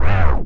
OWWW LOW.wav